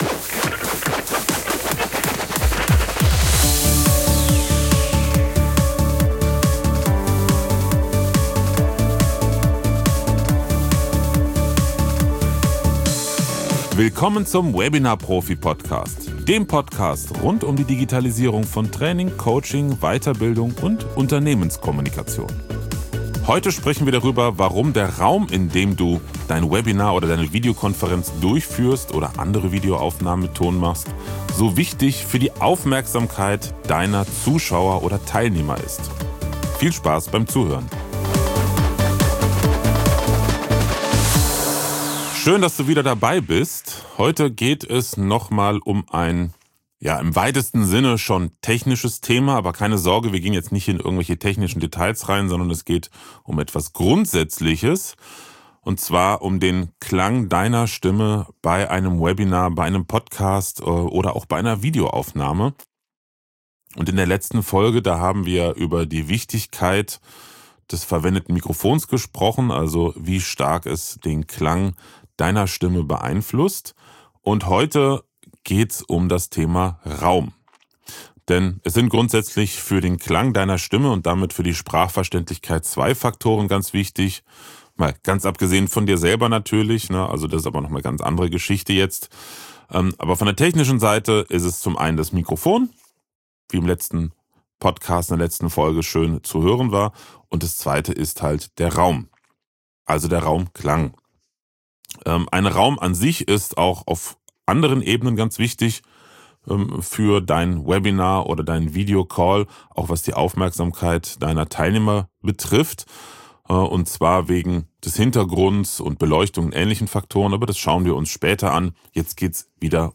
Neben einigem Hintergrundwissen erlebst Du auch in einem direkten Vergleich, wie unterschiedlich Räume klingen! Außerdem bekommst Du wertvolle Tipps, wie Du mit auch mit einfachen Mitteln Abhilfe schaffen kannst!